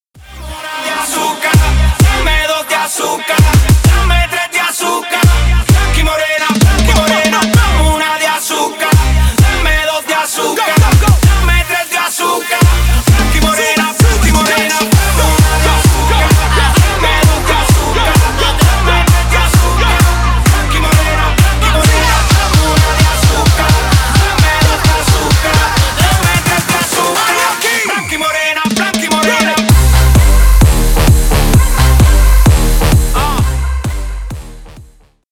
Танцевальные
латинские # клубные